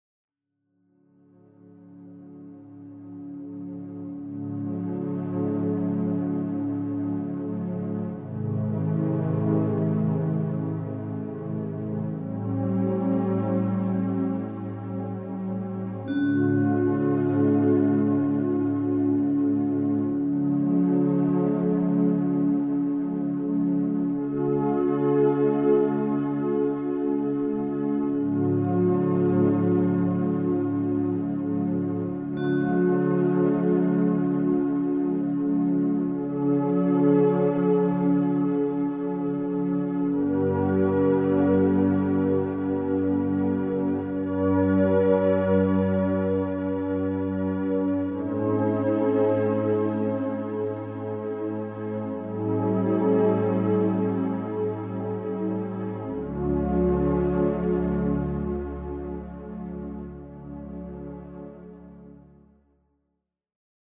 passend abgestimmt auf die 9 Solfeggio-Frequenzen.